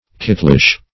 Definition of kittlish.
Kittlish \Kit"tlish\, a.